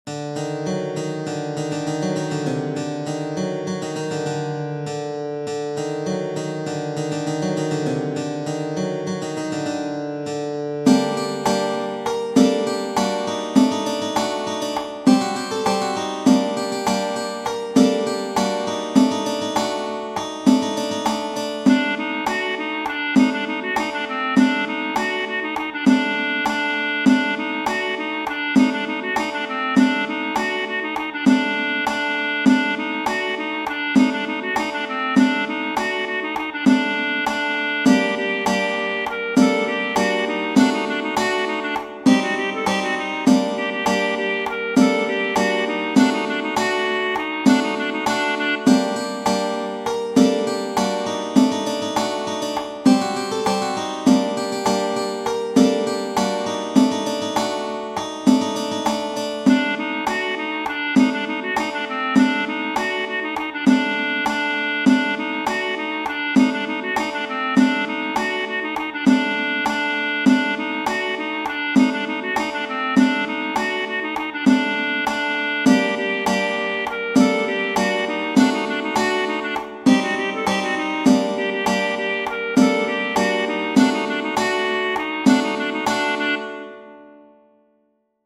Genere: Folk